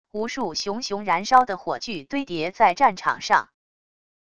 无数熊熊燃烧的火炬堆叠在战场上wav音频